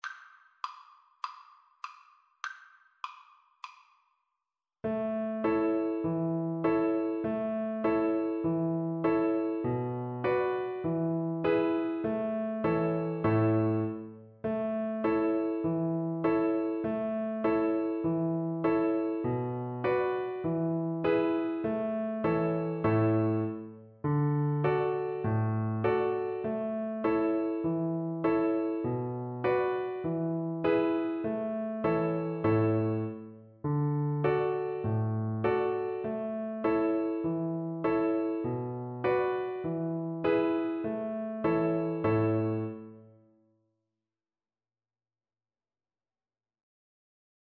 4/4 (View more 4/4 Music)
Traditional (View more Traditional Violin Music)
world (View more world Violin Music)